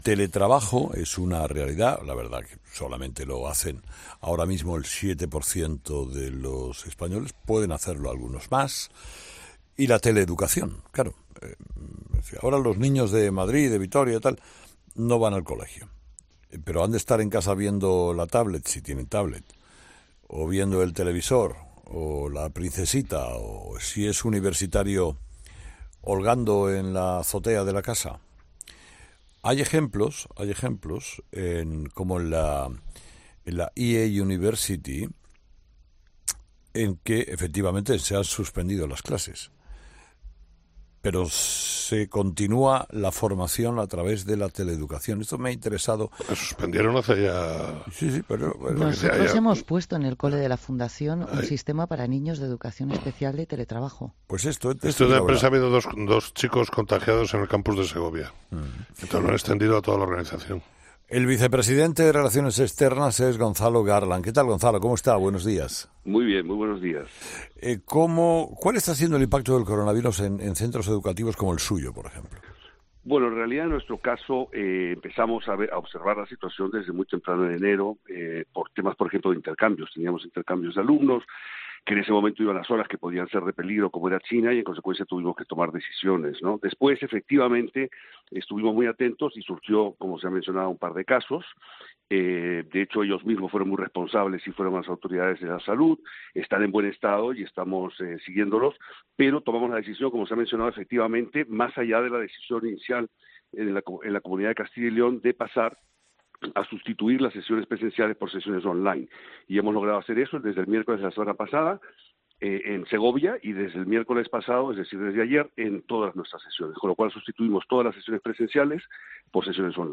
Un profesor explica en COPE cómo se estudia online
Con Carlos Herrera